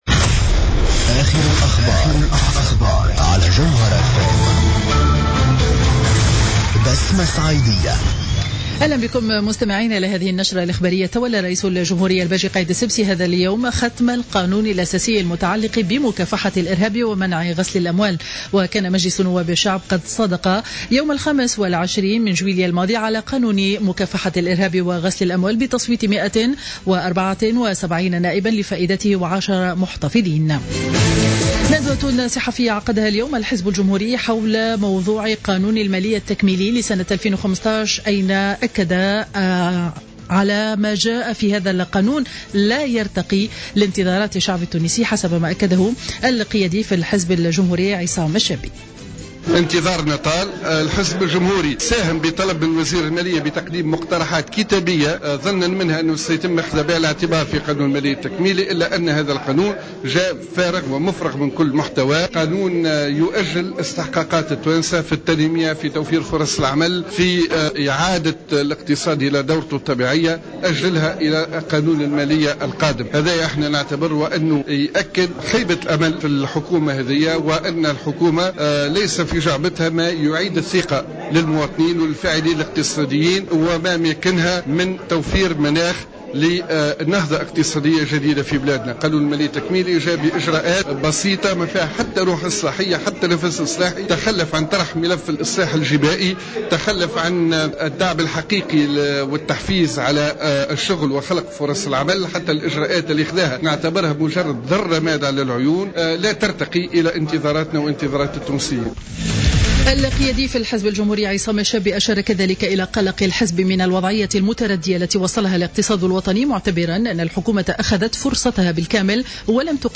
نشرة أخبار منتصف النهار ليوم الجمعة 7 أوت 2015